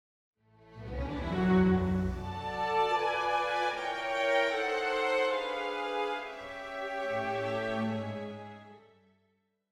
Parallelle forminskede septimakkorder
Felix Mendelssohn Bartoldy, Sinfonia no.10 i h-moll